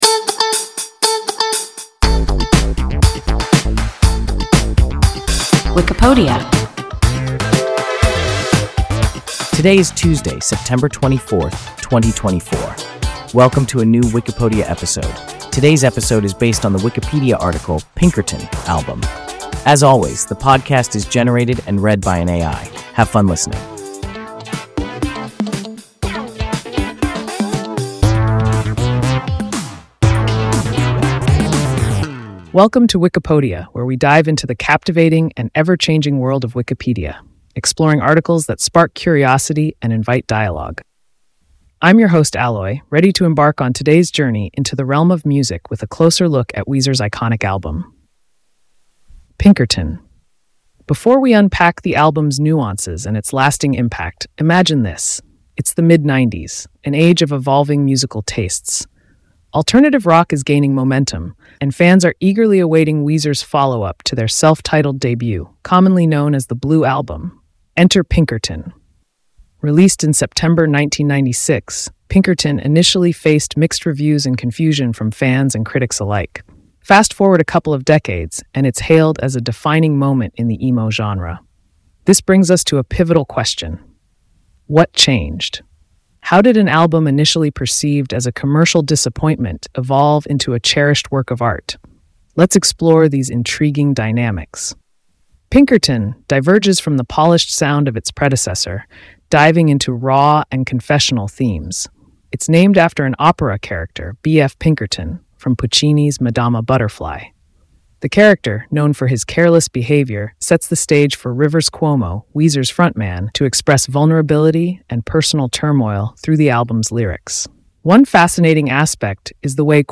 Pinkerton (album) – WIKIPODIA – ein KI Podcast